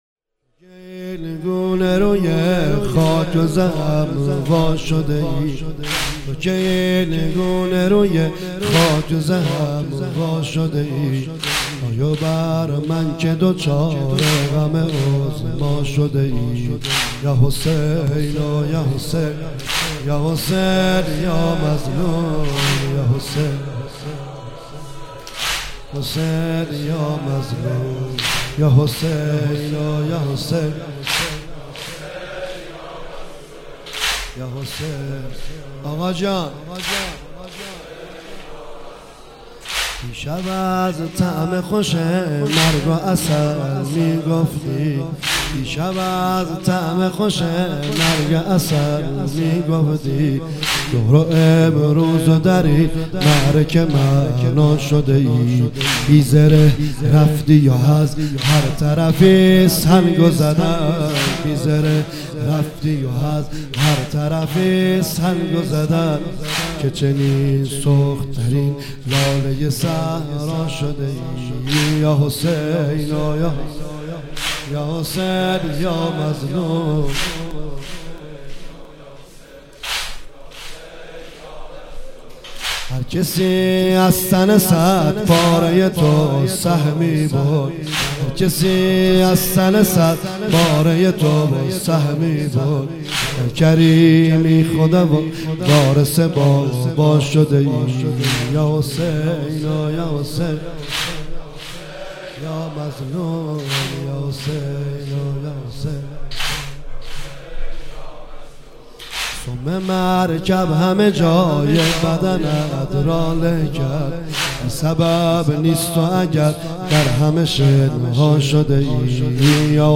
مداحی جدید حاج نریمان پناهی شب ششم محرم۹۸ شهریور ۱۳۹۸ هیئت رزمندگان مکتب الحسین(ع)